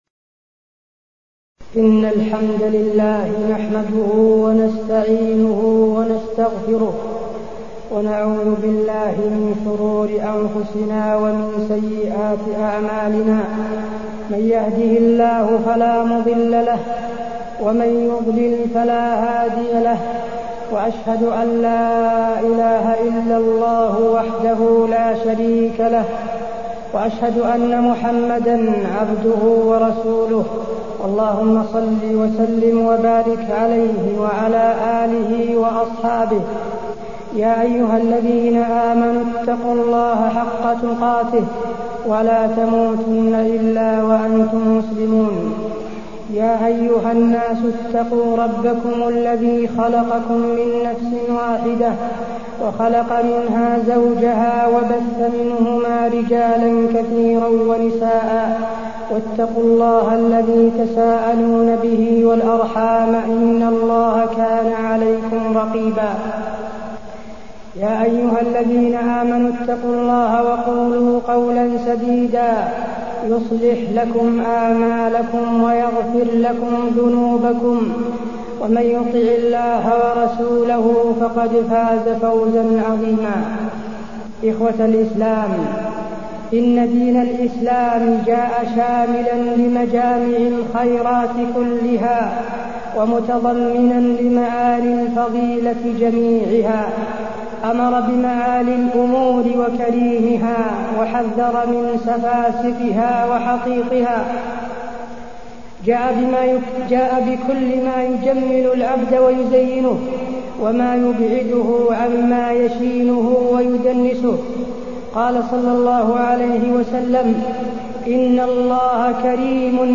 تاريخ النشر ٢٢ ربيع الثاني ١٤٢٢ هـ المكان: المسجد النبوي الشيخ: فضيلة الشيخ د. حسين بن عبدالعزيز آل الشيخ فضيلة الشيخ د. حسين بن عبدالعزيز آل الشيخ الزنا